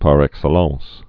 (pär ĕk-sə-läɴs)